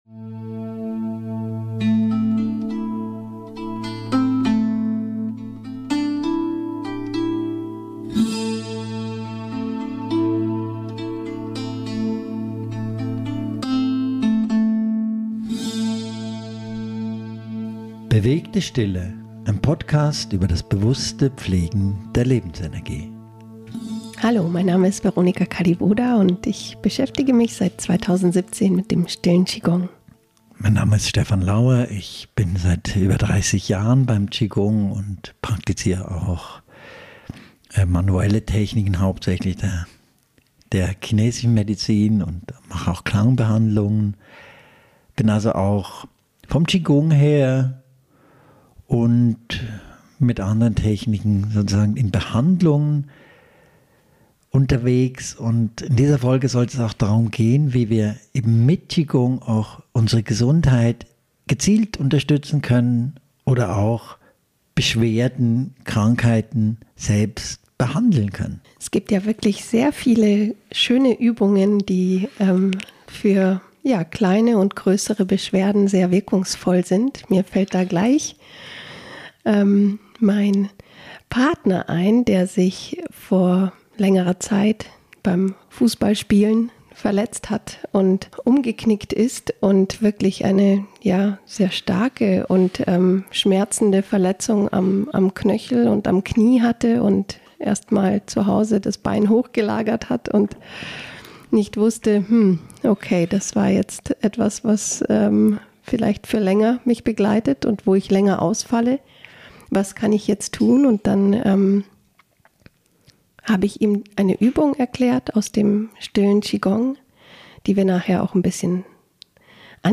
Übungen aus dem Stillen Qi Gong eignen sich ganz besonders, um selbständig kleinere und auch grössere Krankheiten, Beschwerden und Verletzungen anzugehen. Mit angeleiteter Übung.